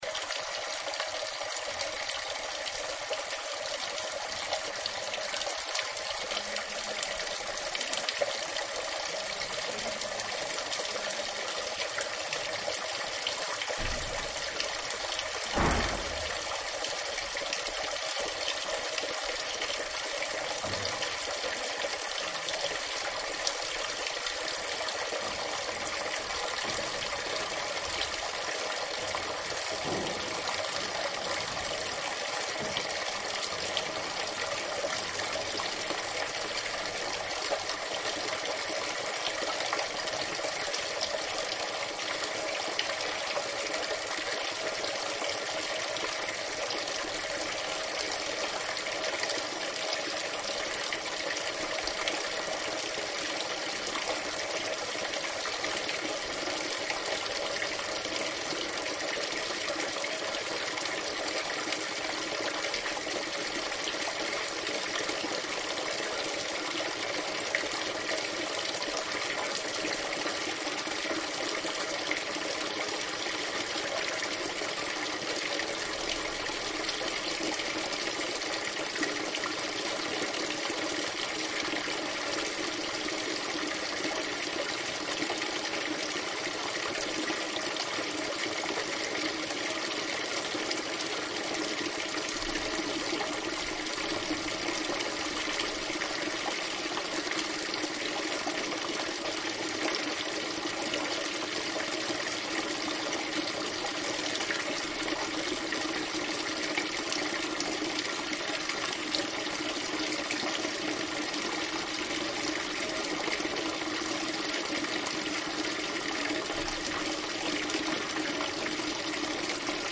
The sound of water in the Carvajales palace
El agua mana por un único caño situado en la boca de un león, flanqueado por dos dragones alados.